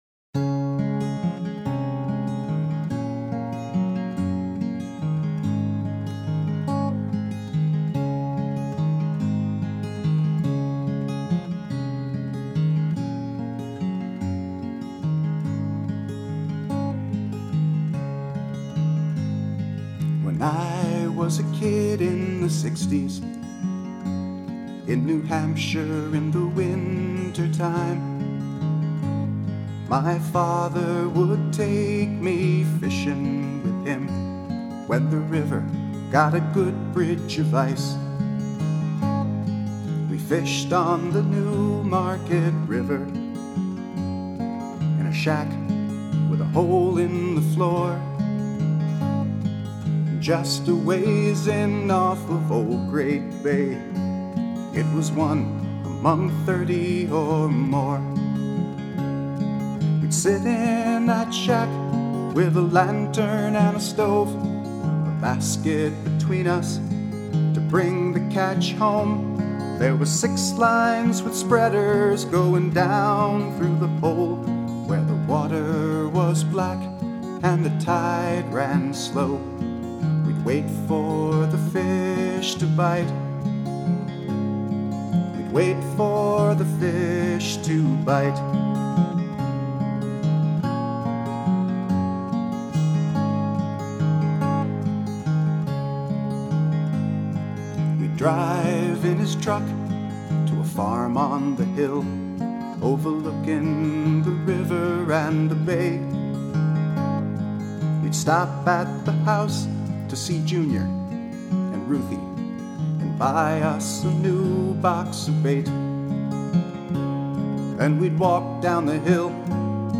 “Winter Of ’92” – words, music, guitar & vocals